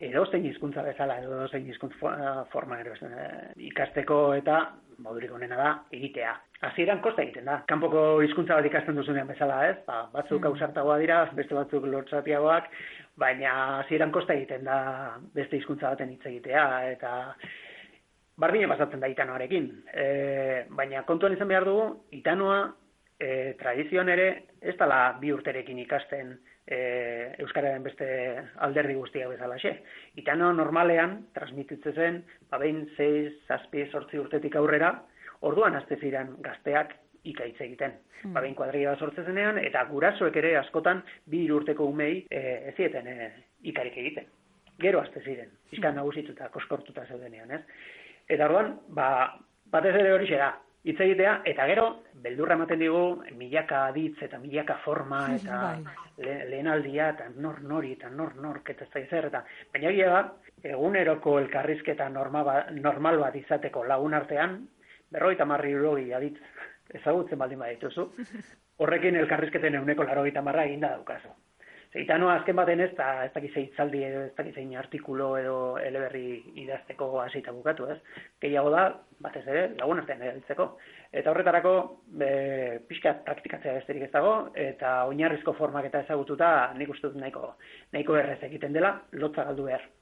Gipuzkoa